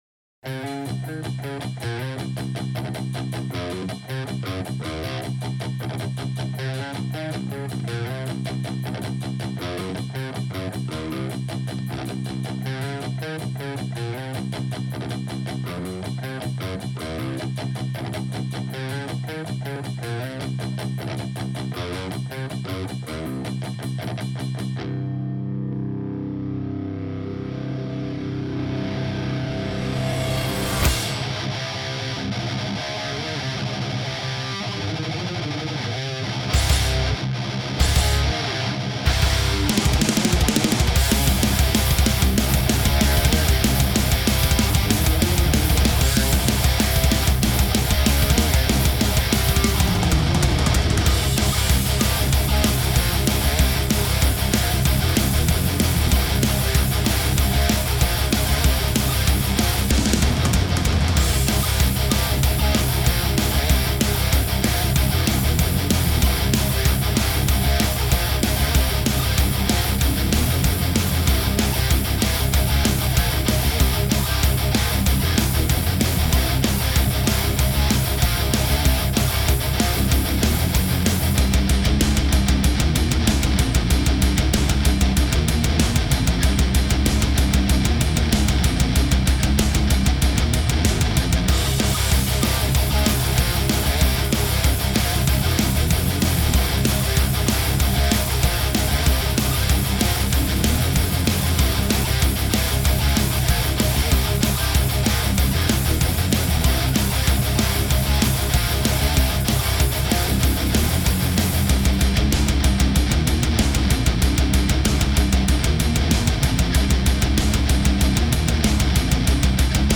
edited a few things. think it sounds better..
Yea maybe a bit to much sizzle on the cymbals/hats but the guitars sound sick as fuck for plugin man.
I thought i may have heard the guitars get a little woofy in parts.
i used EZ drummer for the drums